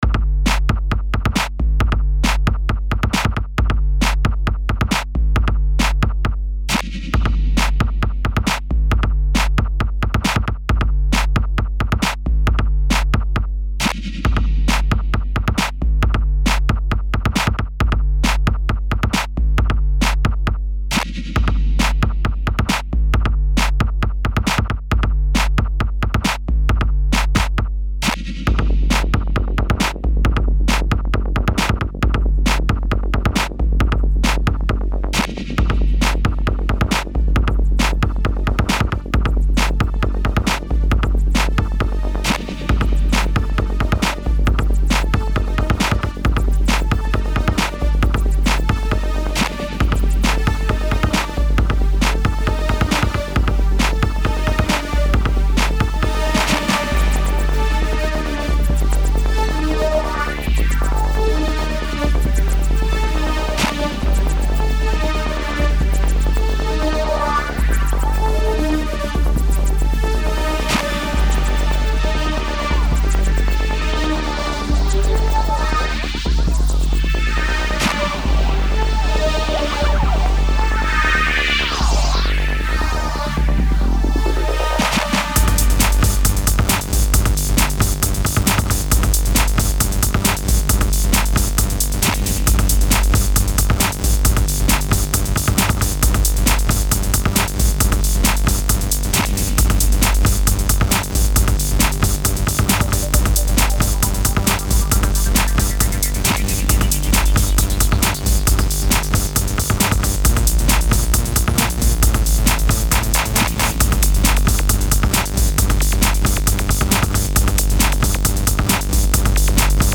So many great elements that combine in a powerful way.